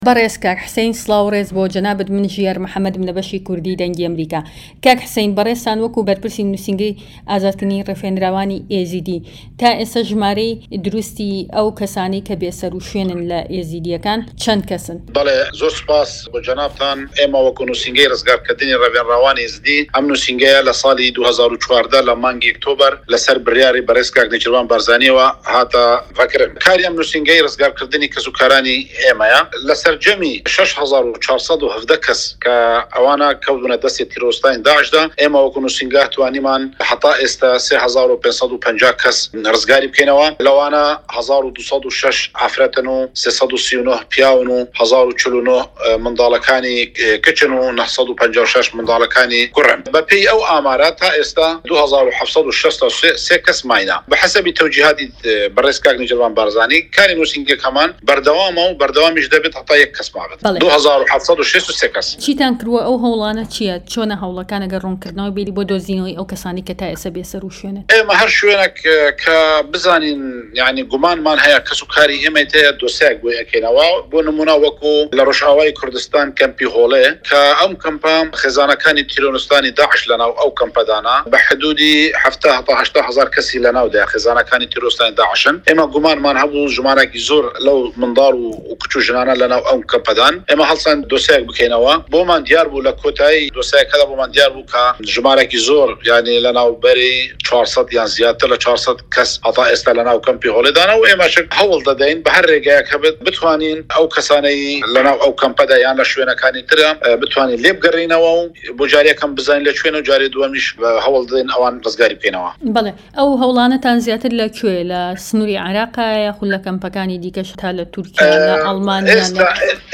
دەقی وتووێژەکەی